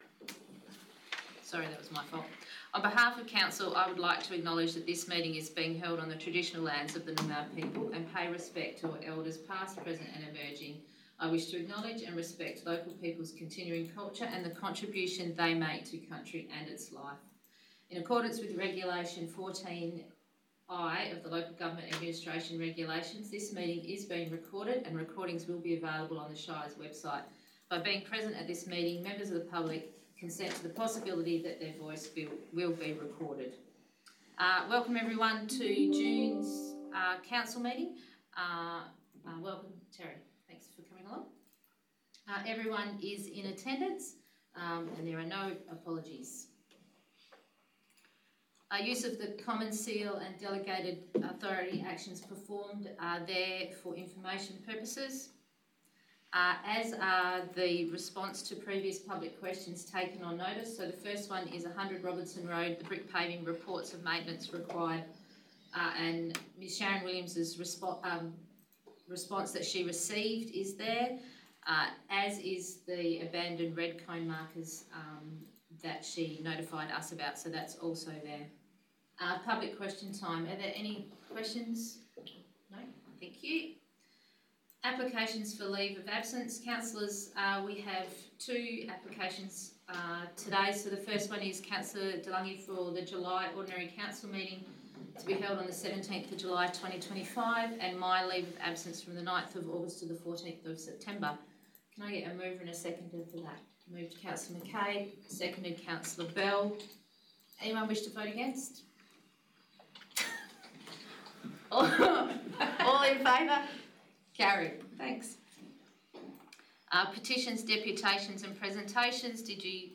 19 June 2025 - Ordinary Meeting of Council » Shire of Brookton
19 June 2025 Ordinary Meeting of Council Recording (27.16 MB)